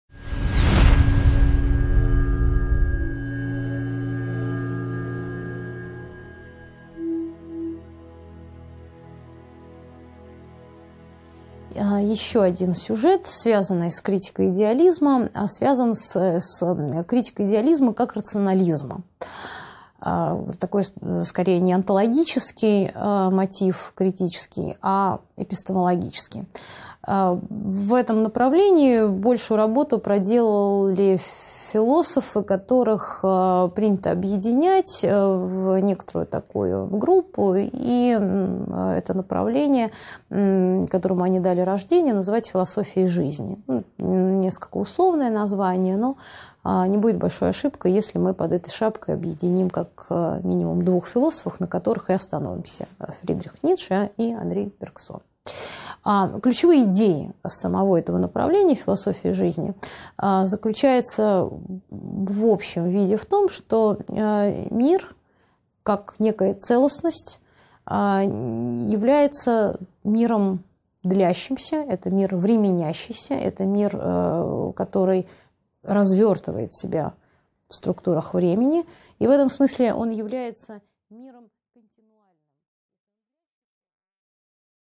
Аудиокнига 15.4 Философия жизни | Библиотека аудиокниг